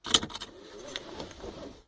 unbuckle.wav